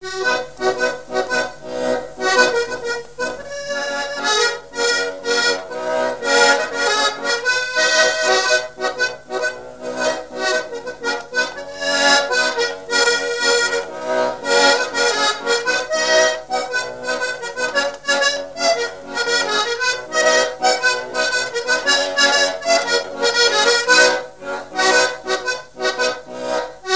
Mémoires et Patrimoines vivants - RaddO est une base de données d'archives iconographiques et sonores.
danse : raspa
Pièce musicale inédite